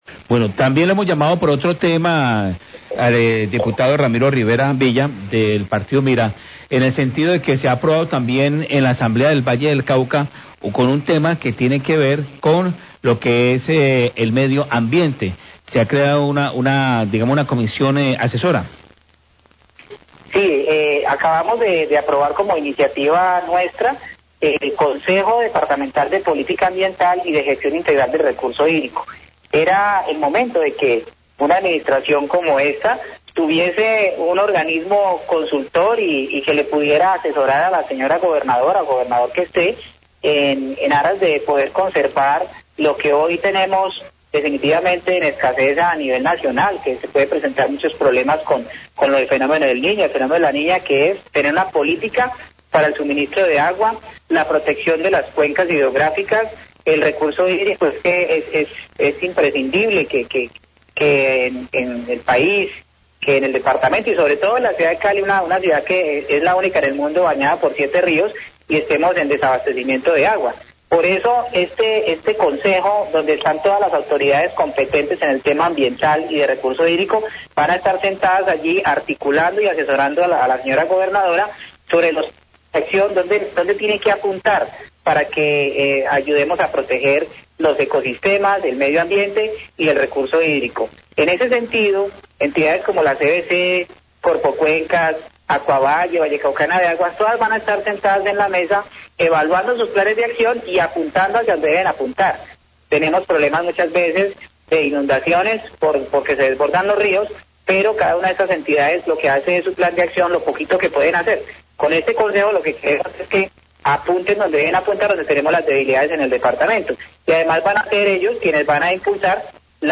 Radio
Aparte de la entrevista al diputado Ramiro Rivera, quien habla sobre la creación de un comité ambiental con políticas para la protección del medio ambiente y el recurso hídrico en el Valle, donde entidades como la CVC, Corpocuencas, Acuavalle y Vallecaucana de Aguas, estarán en la mesa con planes de acción.